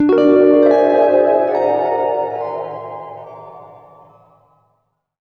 GUITARFX17-R.wav